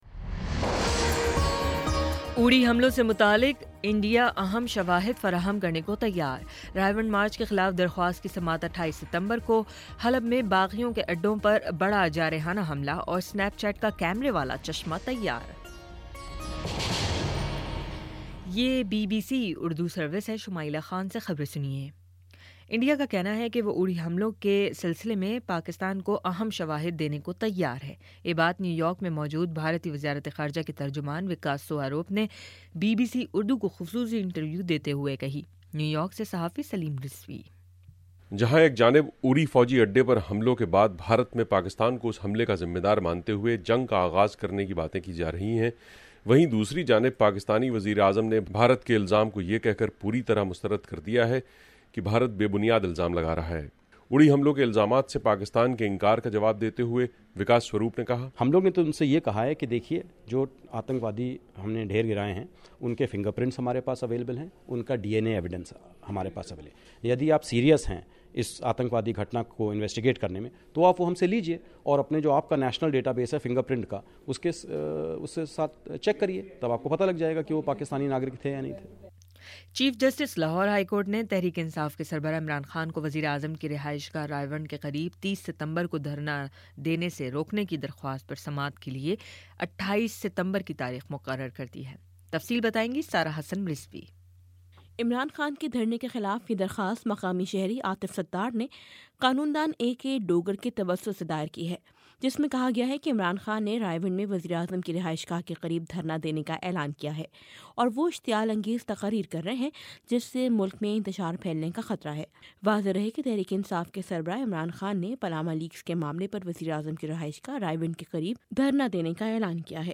ستمبر24 : شام سات بجے کا نیوز بُلیٹن